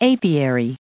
added pronounciation and merriam webster audio
239_apiary.ogg